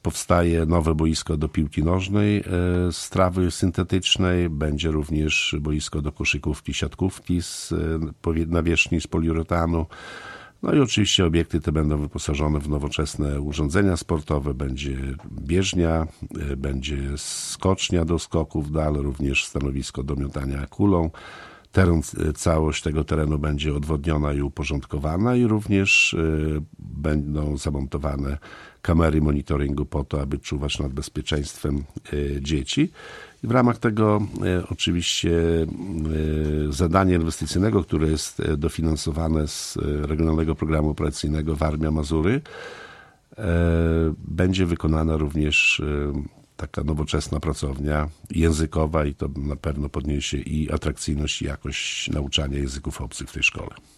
Szczegóły przedstawił w środę (20.06) gość Radia 5 Wacław Olszewski, burmistrz Olecka.
Wacław-Olszewski1.mp3